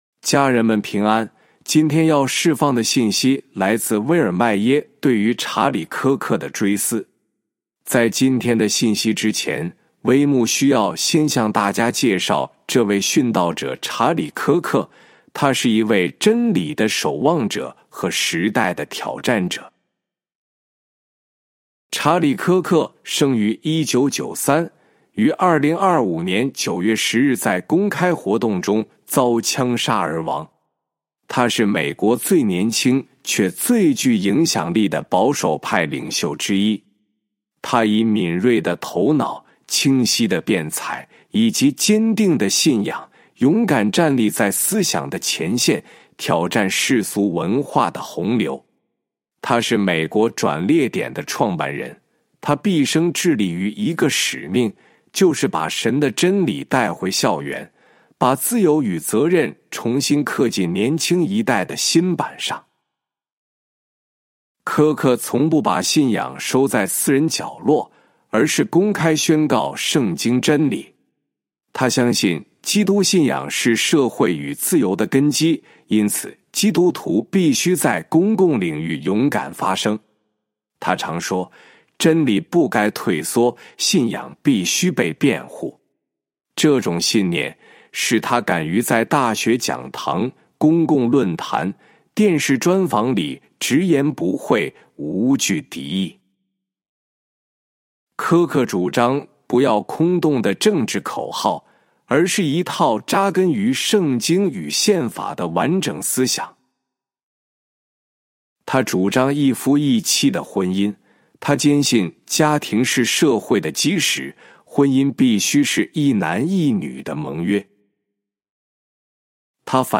本篇是由微牧之歌翻译撰稿祷告及朗读 仍然说话的血：查理・柯克的追思 第一音源 第二音源 查理·柯克：真理的守…